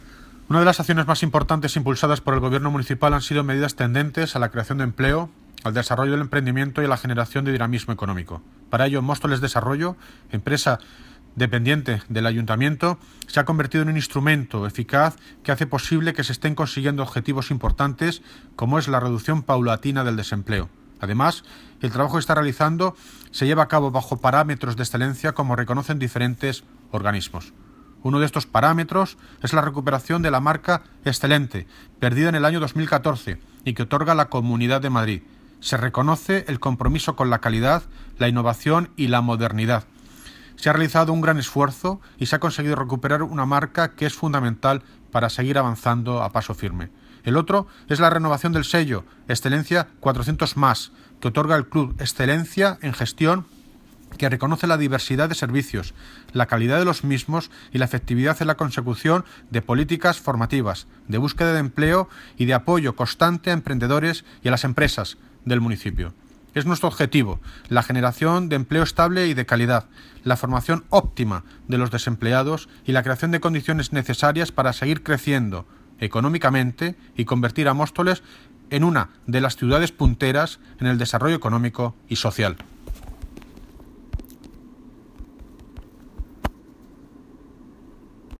Audio - David Lucas (Alcalde de Móstoles) Sobre Móstoles Desarrollo